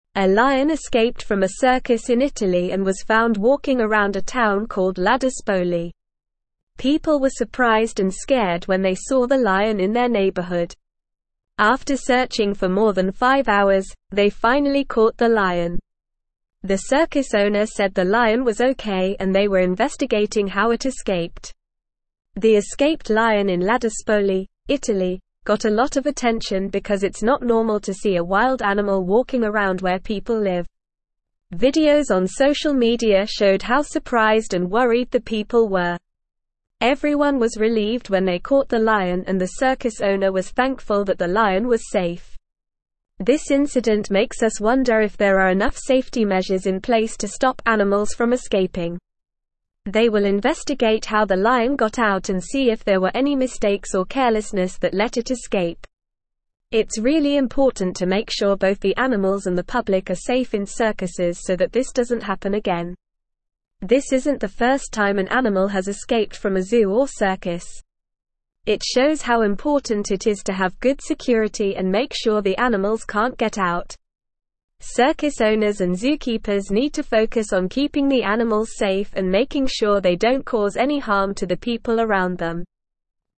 Normal
English-Newsroom-Upper-Intermediate-NORMAL-Reading-Circus-Lion-Escapes-in-Italy-Causes-Town-Panic.mp3